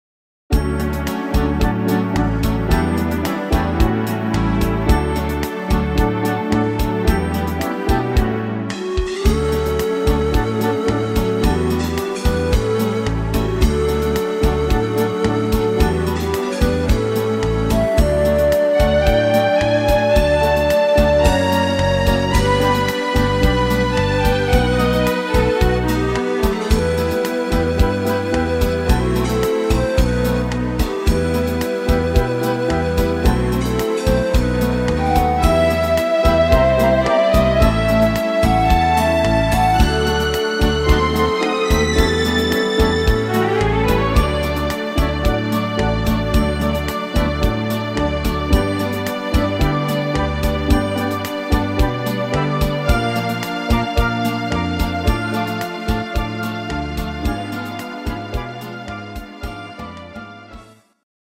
Rhythmus  Rhumba
Art  Instrumental Orchester, Tanzschule